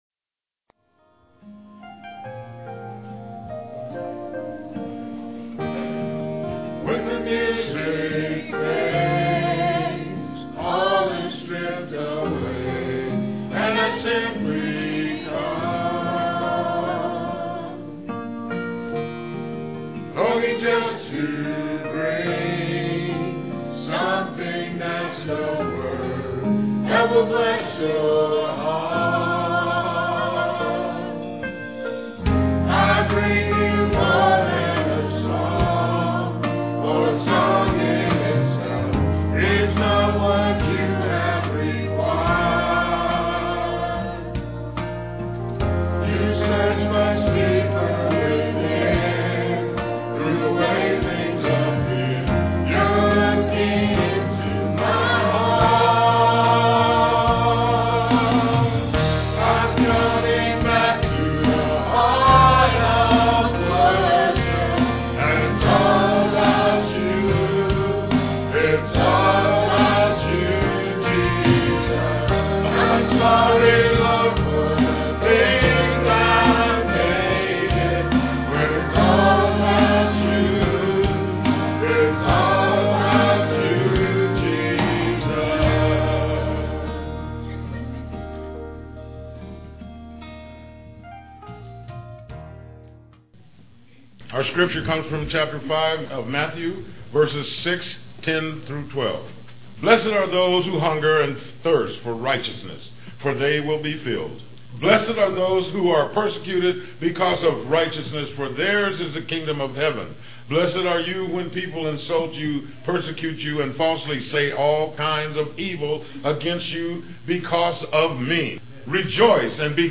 Piano and organ duet